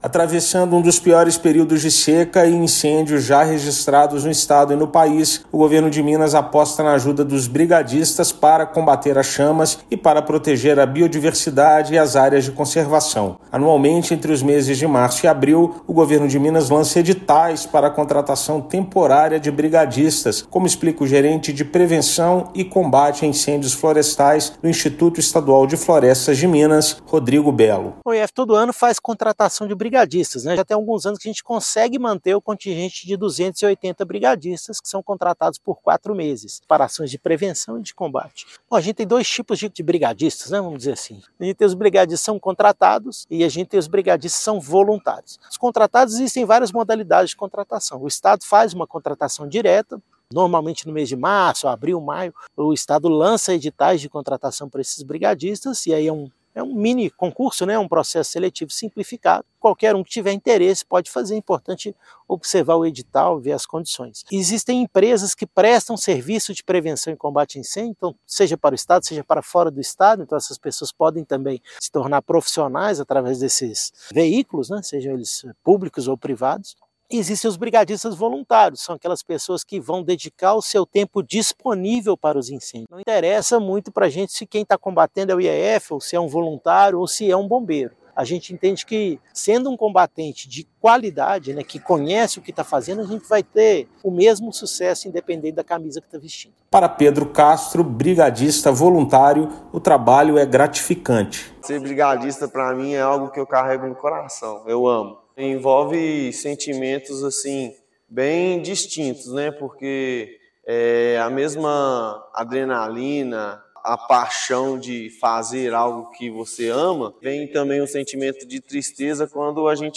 Contratados por meio de editais do Governo de Minas, combatentes profissionais e voluntários não medem esforços para proteger unidades de conservação do fogo. Ouça matéria de rádio.